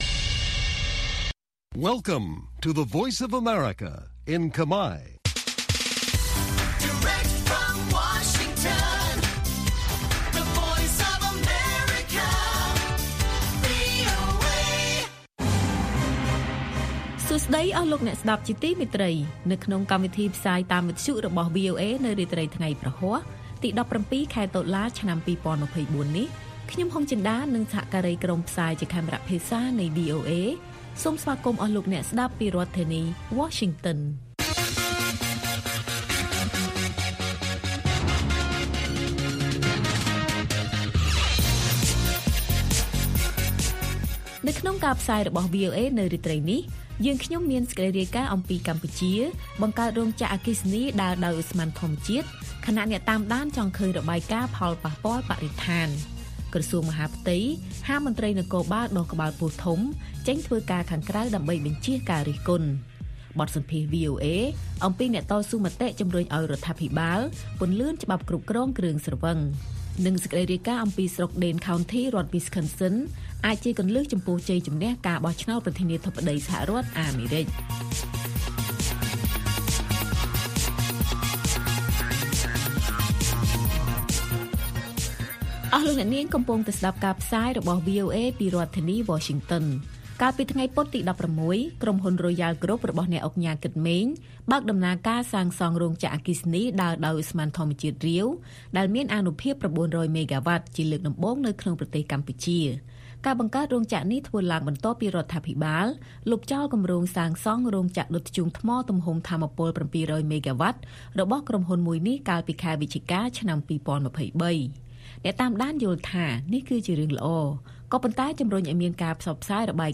ព័ត៌មាននៅថ្ងៃនេះមានដូចជា កម្ពុជាបង្កើតរោងចក្រអគ្គិសនីដើរដោយឧស្ម័នធម្មជាតិ ខណៈអ្នកតាមដានចង់ឃើញរបាយការណ៍ផលប៉ះពាល់បរិស្ថាន។ បទសម្ភាសន៍វីអូអេអំពីអ្នកតស៊ូមតិជំរុញឲ្យរដ្ឋាភិបាលពន្លឿនច្បាប់គ្រប់គ្រងគ្រឿងស្រវឹង និងព័ត៌មានផ្សេងៗទៀត៕